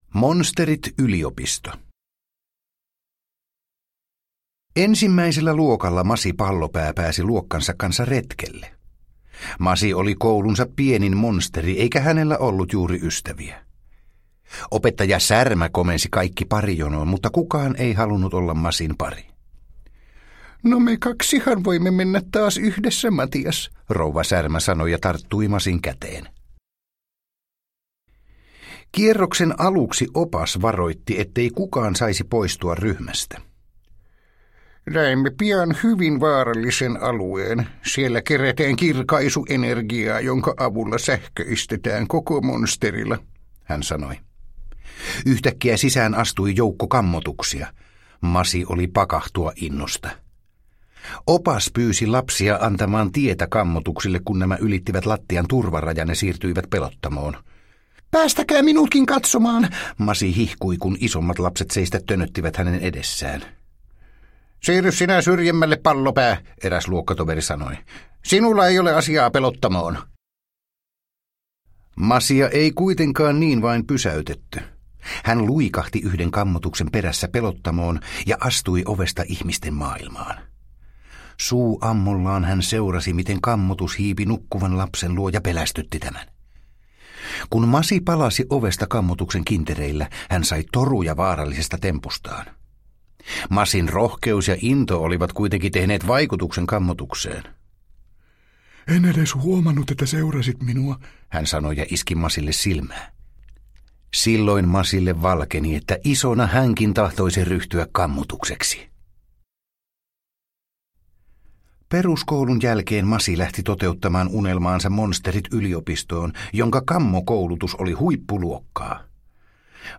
Monsterit-yliopisto – Ljudbok – Laddas ner